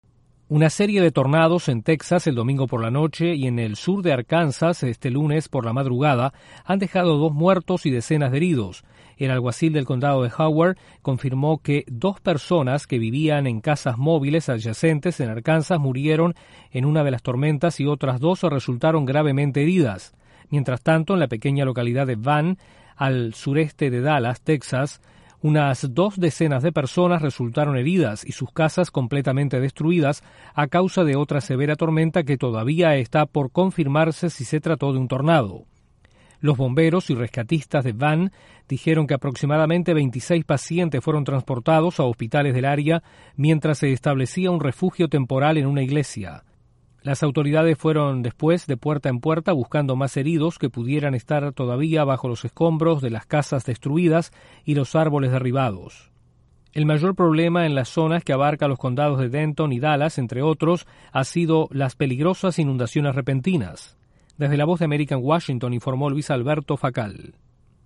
Tornados en el sur de Estados Unidos causan por lo menos dos muertos y decenas de heridos. Desde la Voz de América en Washington informa